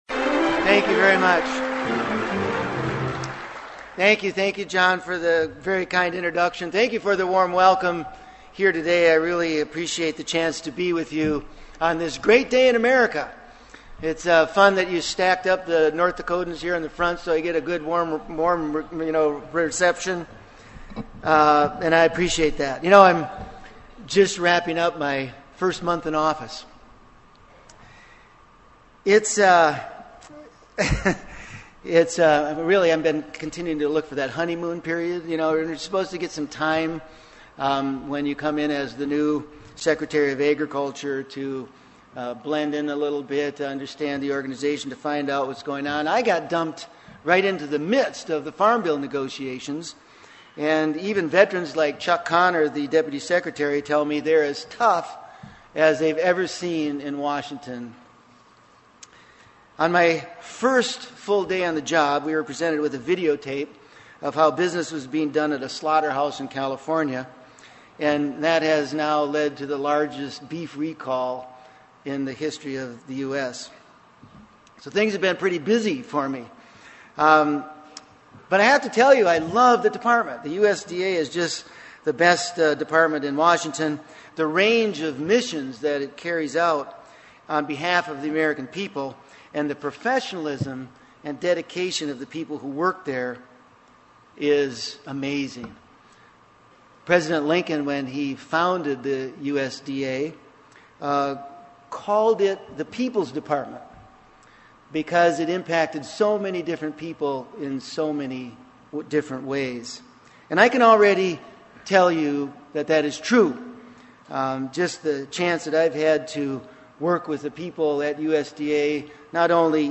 Southeast Agnet was represented at the 2008 Commodity Classic in Nashville last week, along with 144 other ag media and more than 4500 total attendees.
The man of the hour was new agriculture secretary Ed Schafer, who spoke at the general session and then held a press conference for the media. Main topics were the farm bill, commodity prices, agriculture and energy, and the beef recall.